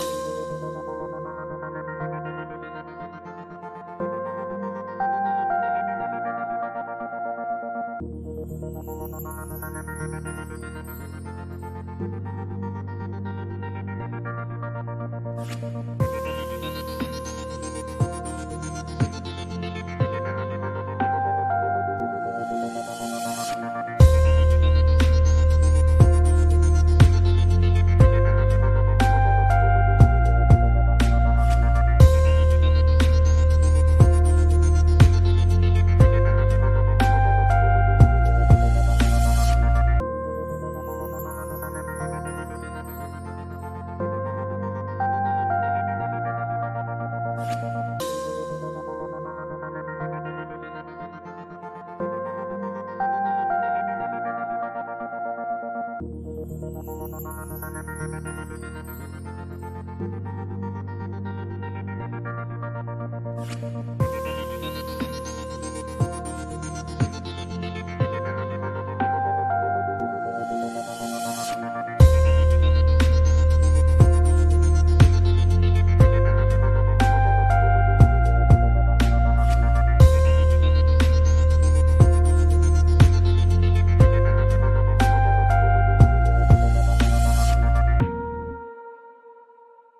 Ambient_0328_9.mp3